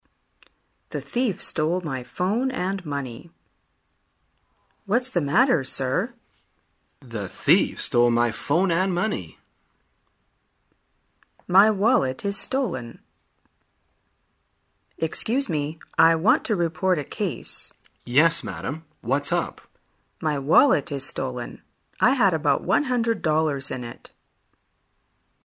旅游口语情景对话 第334天:如何表达被偷